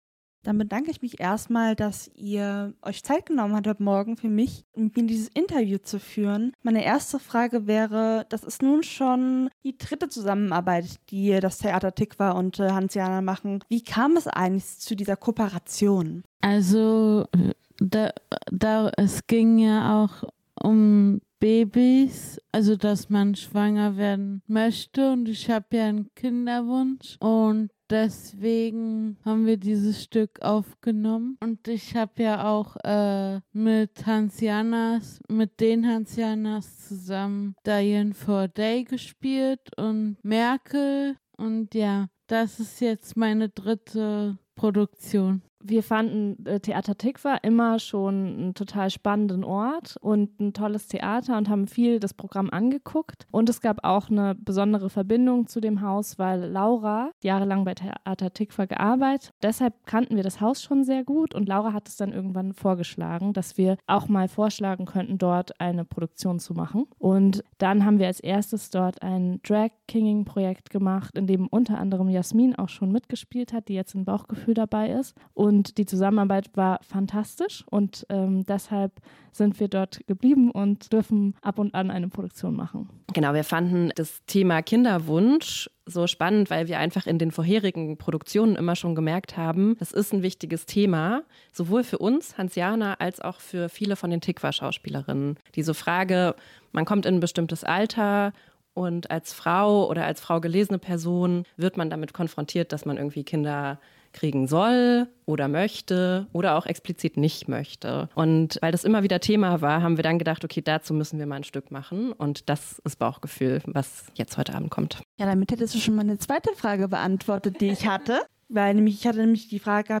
Interview_TheaterThikwa_und_Hannsjana_überarbeitet.mp3